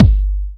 Track 05 - Kick OS 01.wav